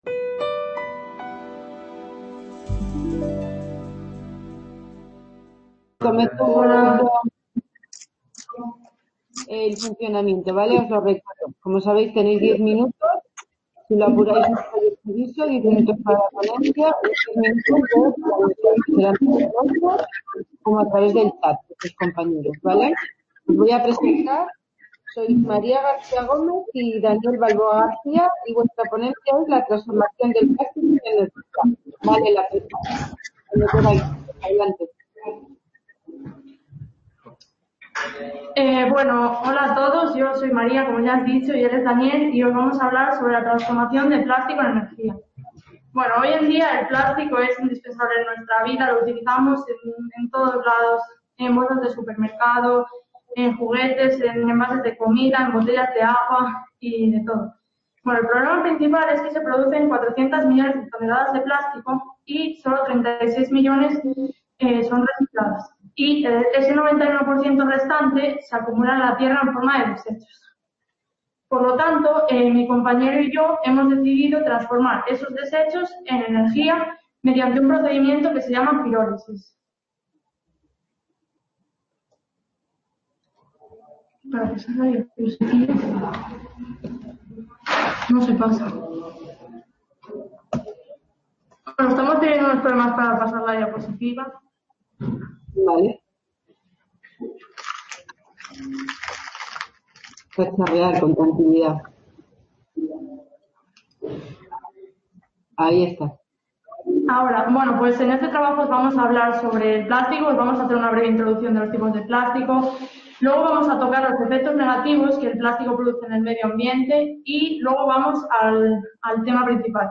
Description Congreso organizado por La Fábrica de Luz. Museo de la Energía junto con la ULE, la UNED y en colaboración con FECYT que se desarrolla en 3 salas CA Ponferrada - 2 Edición Congreso de Jóvenes Expertos.